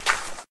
default_sand_footstep.1.ogg